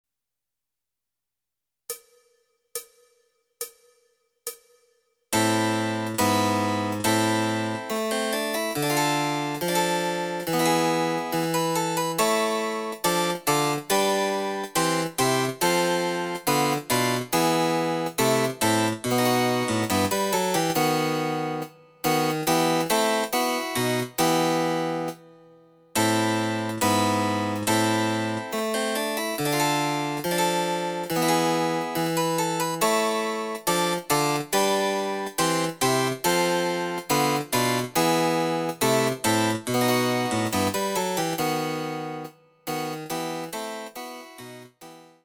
推奨テンポの伴奏
Electoric Harpsichord